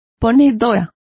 Also find out how ponedoras is pronounced correctly.